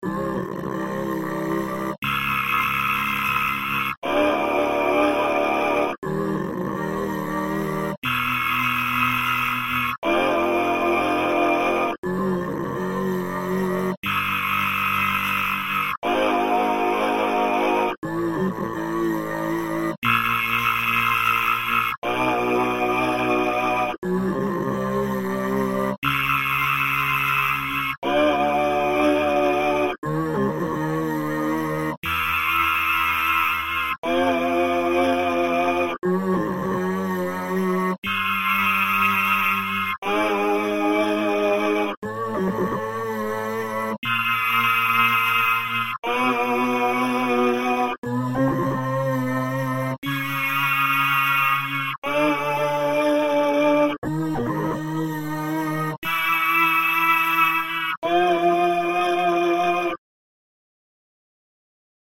arch chromatics.mp3